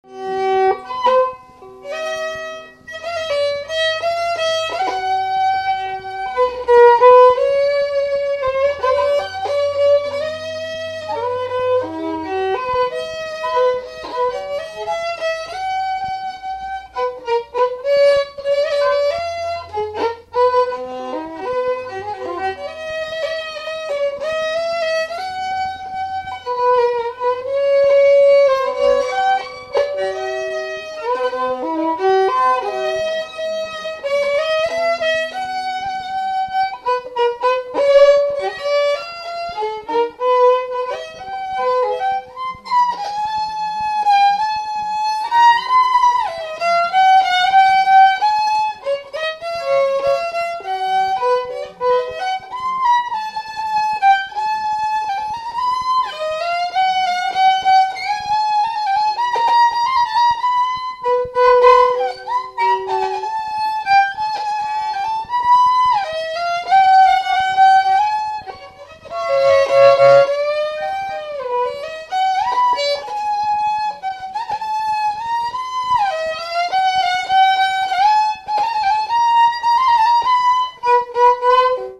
Saint-Louis ( Plus d'informations sur Wikipedia ) Réunion
Instrumental Fonction d'après l'analyste gestuel : à marcher
Pièce musicale inédite